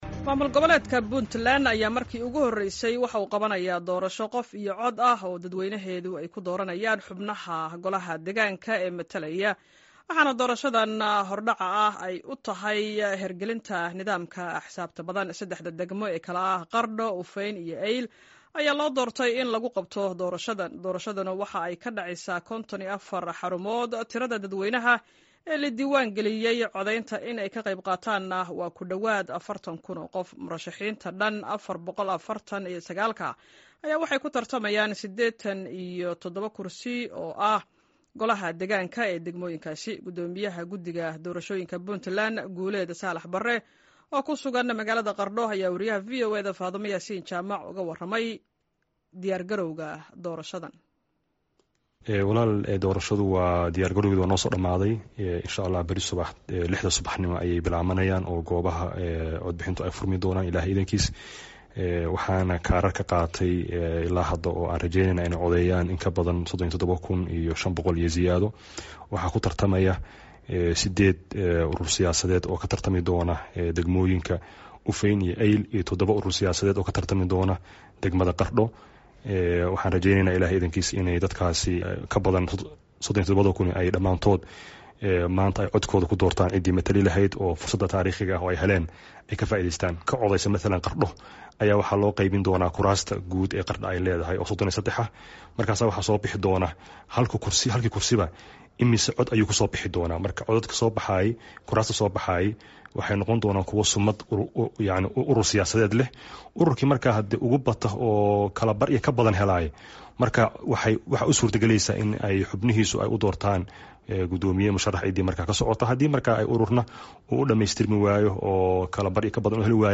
Gudoomiyaha gudiga Doorashooyinka Puntlan Guuleed Saalax Barre oo ku sugan magaalada Qardho